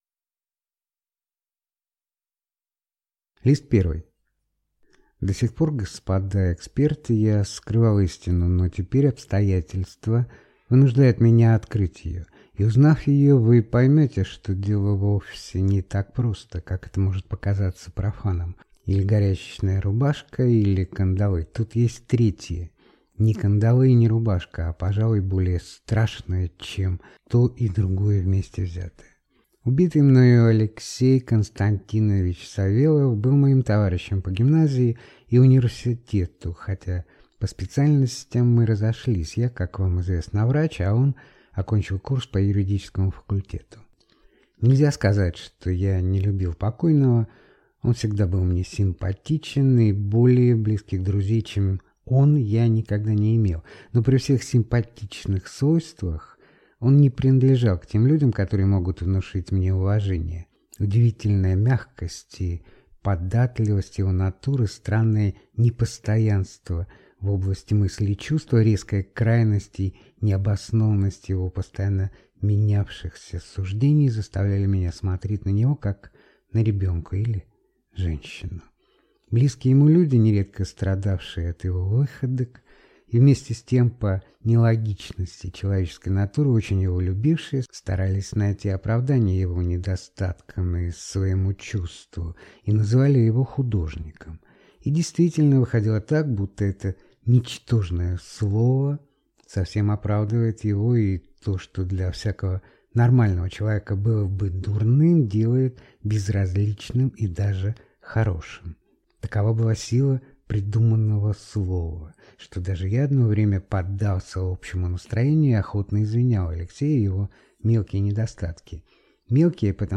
Аудиокнига Мысль | Библиотека аудиокниг